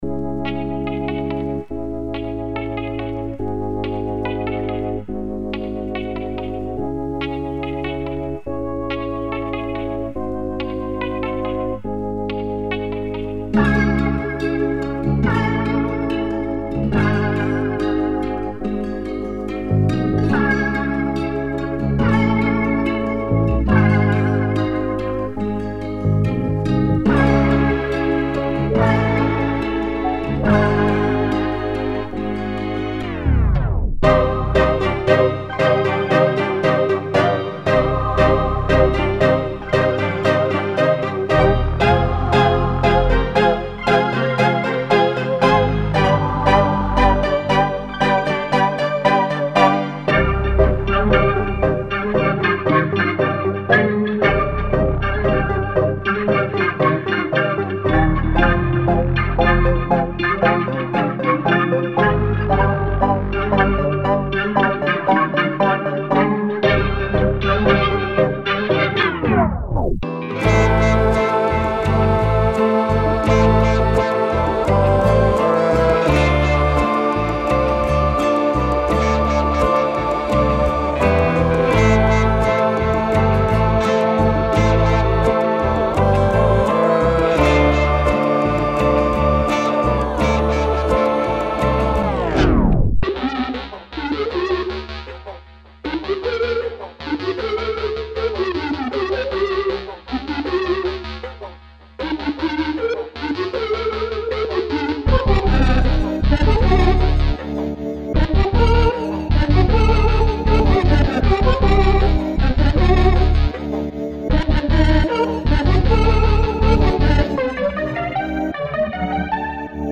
Hip Hop
黑暗，阴沉，险恶，悲伤，诡异，邪恶，
所有文件都采用24bit /立体声/ wav格式，与每台DAW和鼓机兼容，并且100％免版税。